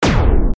Paradise/sound/weapons/pulse.ogg
Added new weapon sounds: blaster, laser, pulse, wave, emitter, and one for the marauder canon.